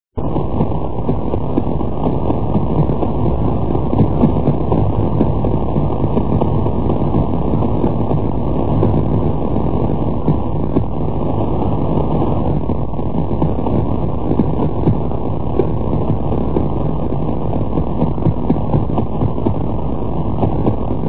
Las señales captadas, no serían audibles, si no se las modulara a su llegada en el receptor.
Los que siguen son 3 Pulsares tomados con la antena de 100 m. de Green Back.
Período: 0,253  seg.
Telescopio: NRAO 92m